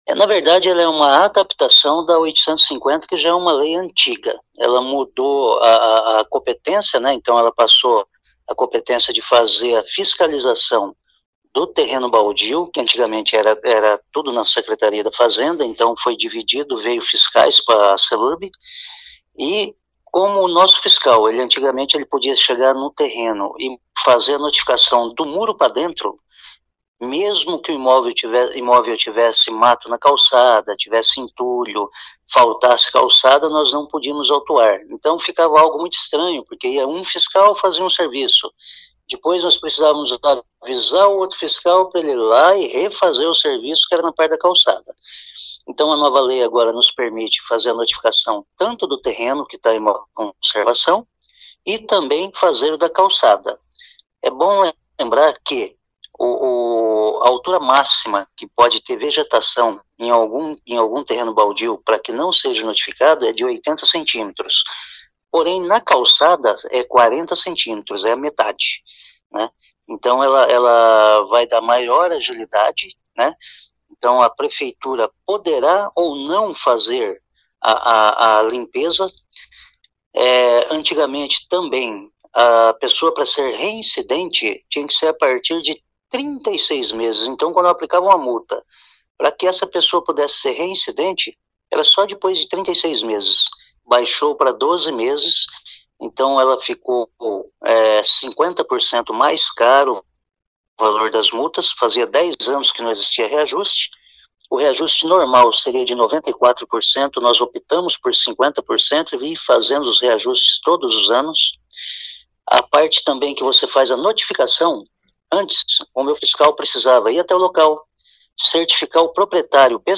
Ouça o que diz o secretário de Infraestrutura e Limpeza Urbana, Vagner Mussio.